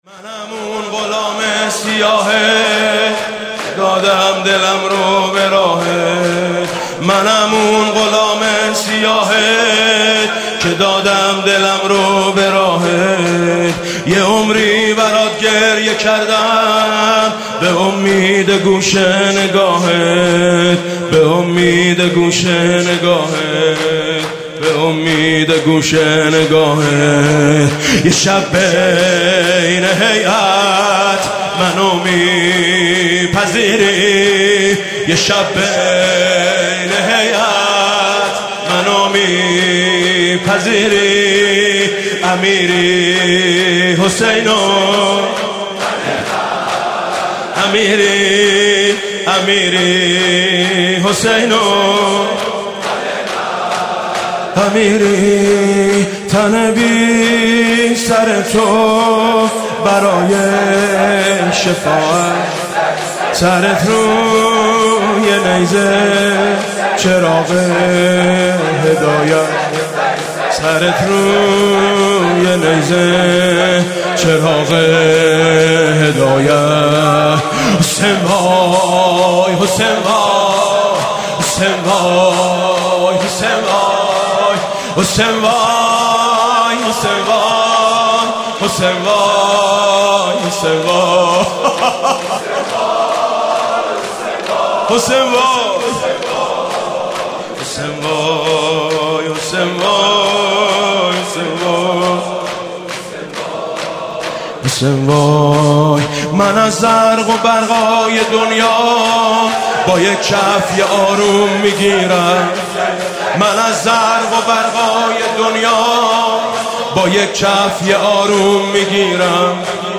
هیئت میثاق با شهدا